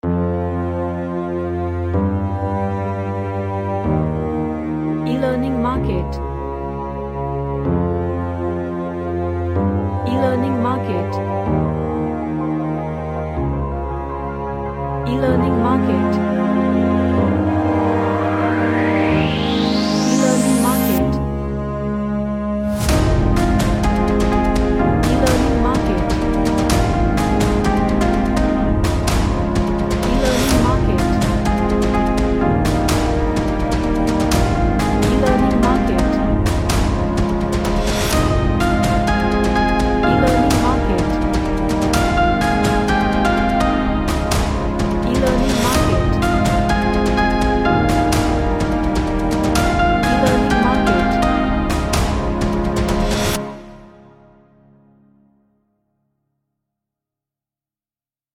A nice melodic cinematic track
Emotional